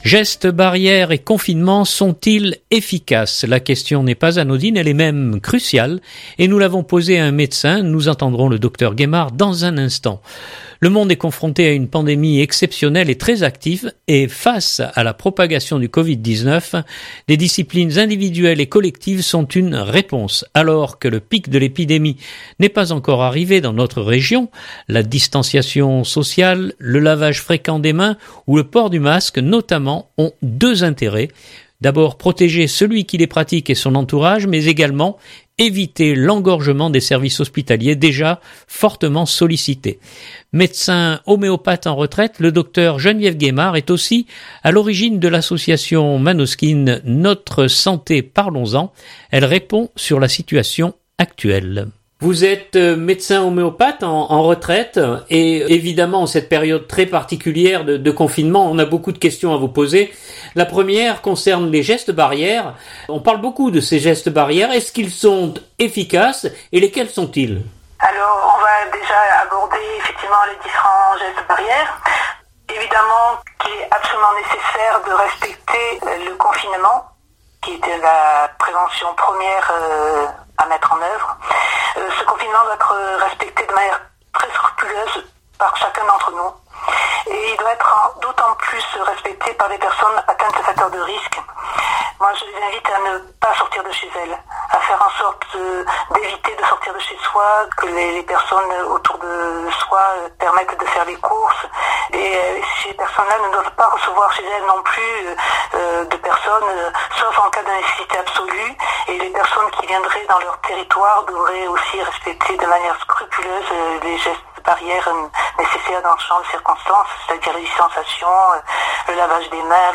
La question n’est pas anodine, elle est même cruciale et nous l’avons posée à un médecin.
Elle répond sur la situation actuelle. 2020-03-31